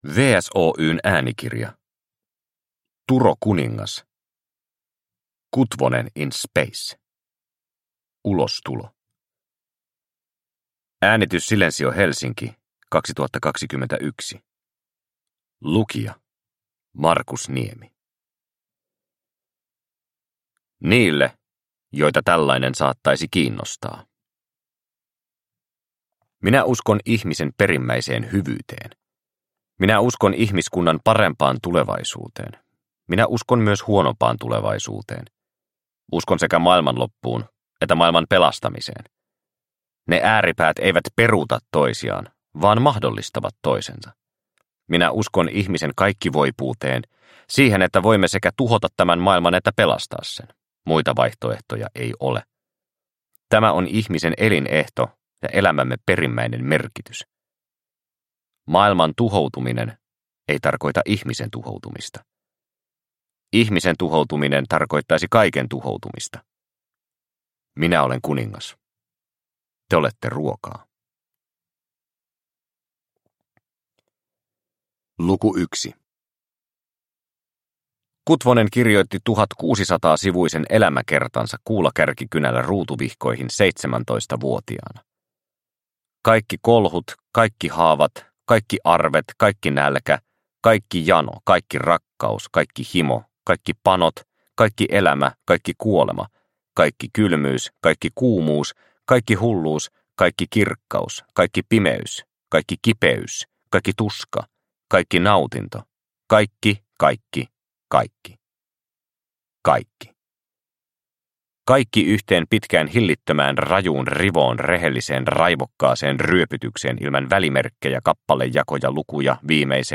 Kutvonen in Space – Ljudbok – Laddas ner